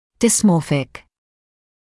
[dɪs’mɔːfɪk][дис’моːфик]дисморфический